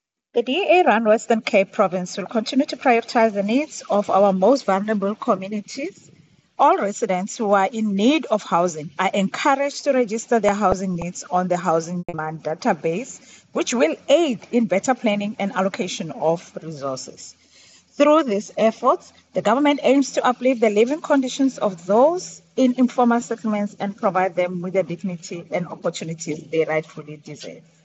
attached an audio clip by MPP Matlhodi Maseko